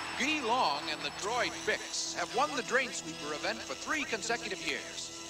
Drainsweeper_Announcer.ogg